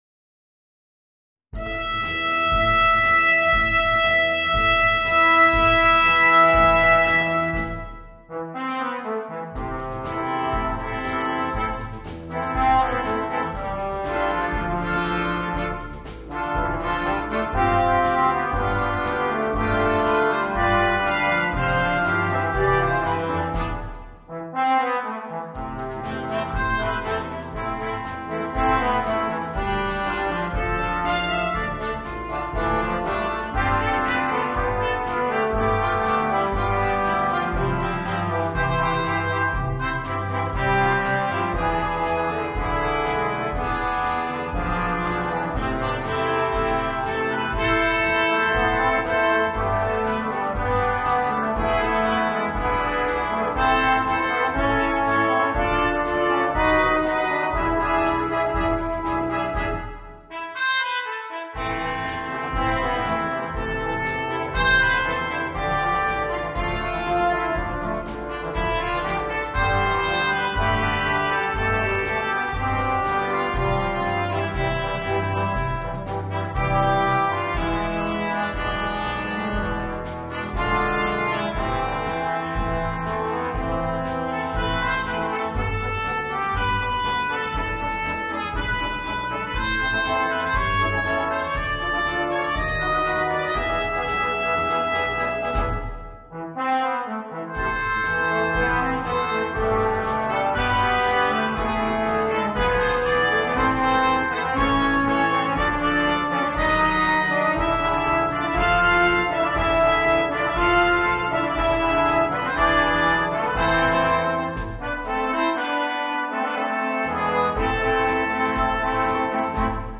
для брасс-бэнда.